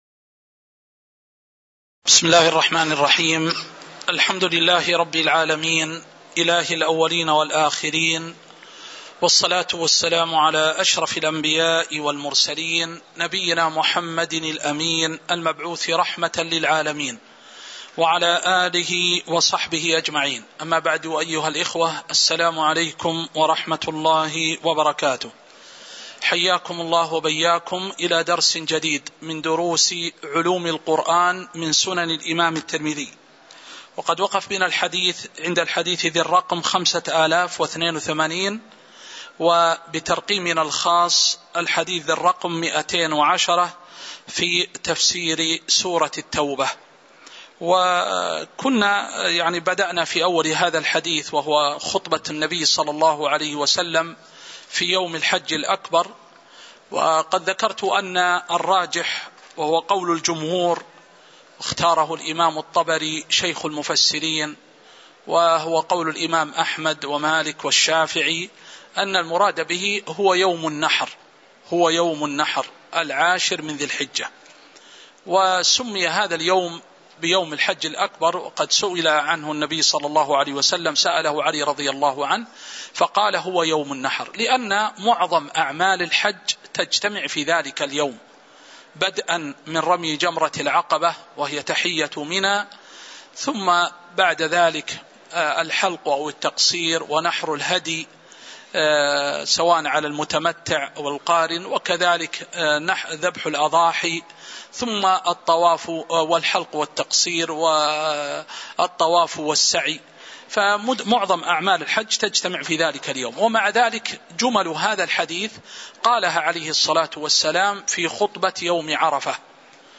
تاريخ النشر ٢٣ ربيع الثاني ١٤٤٣ هـ المكان: المسجد النبوي الشيخ